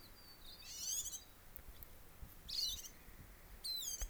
heard this bird call that I've described as "unknown cackler".
unknown_cackler.wav